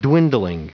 Prononciation du mot dwindling en anglais (fichier audio)
Prononciation du mot : dwindling